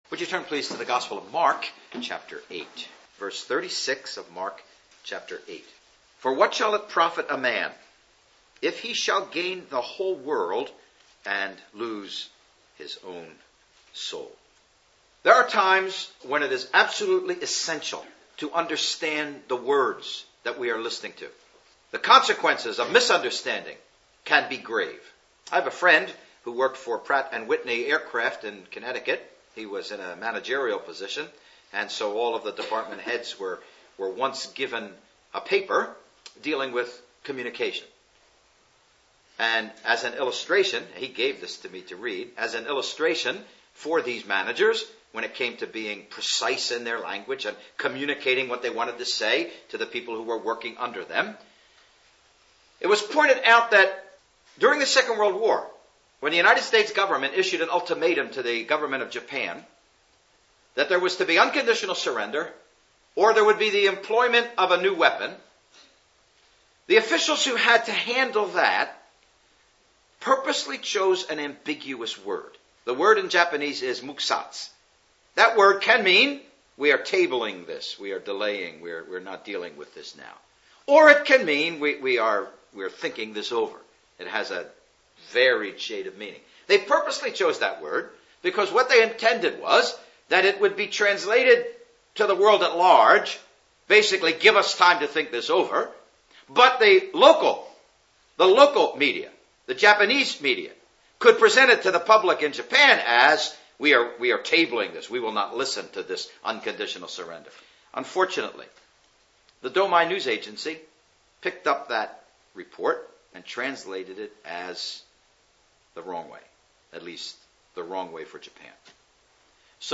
(Message preached 7th Nov 2008 in Ambrosden Village Hall)